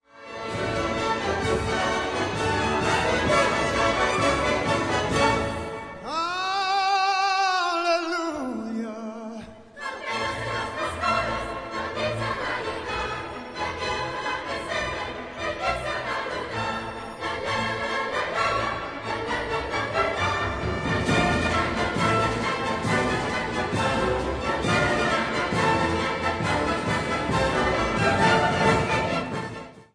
key: A-major